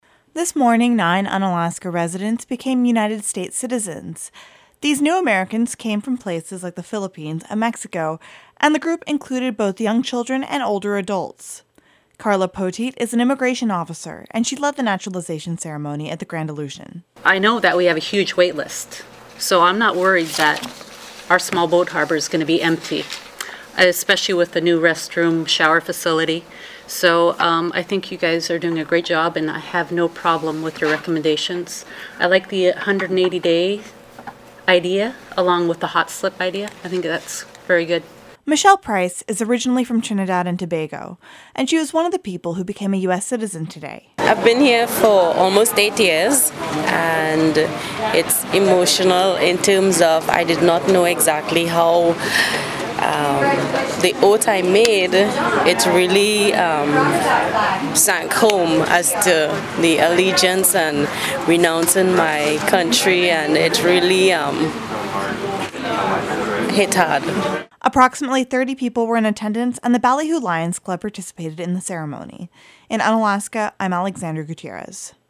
By KUCB News